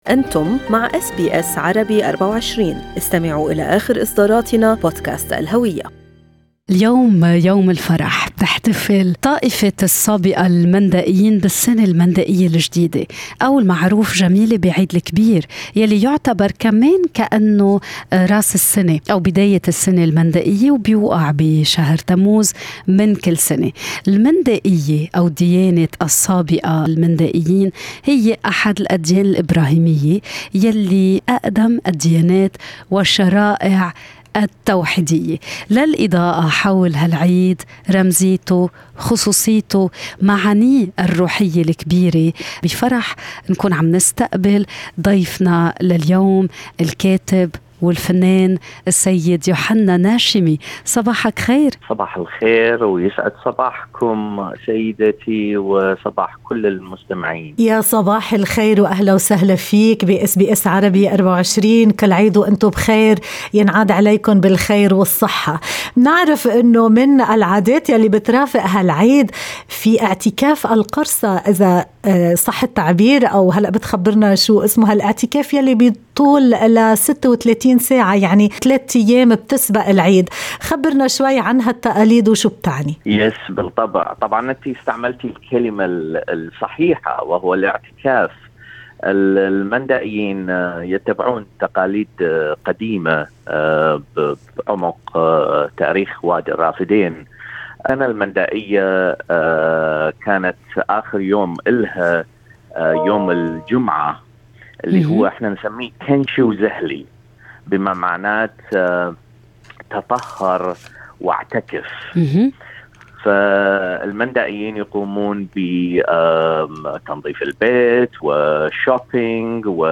هذا الحوار